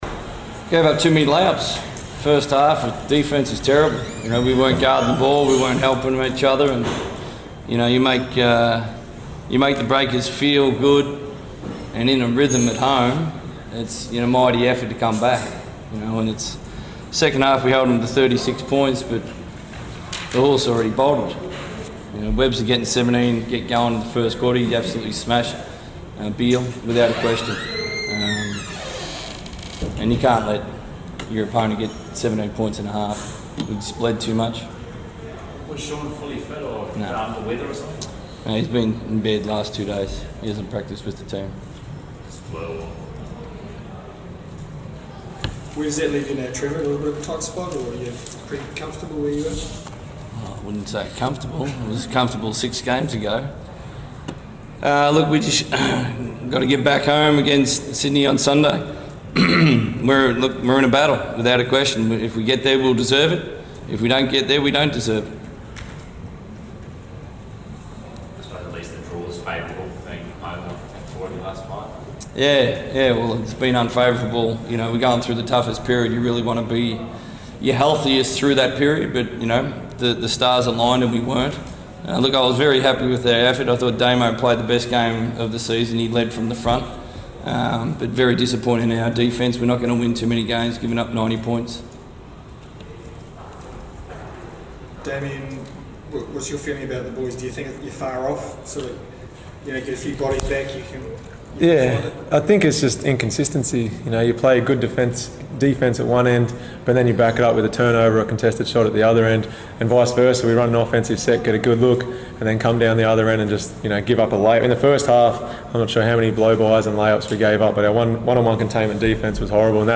Coach Trevor Gleeson and captain Damian Martin speak to the media following the Perth WIldcats defeat versus the New Zealand Breakers.